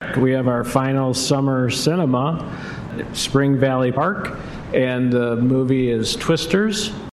Kalamazoo City Manager Jim Ritsema says the Parks Department has a couple of events planned. A movie will be shown tonight, and they have picked the perfect film to watch outdoors.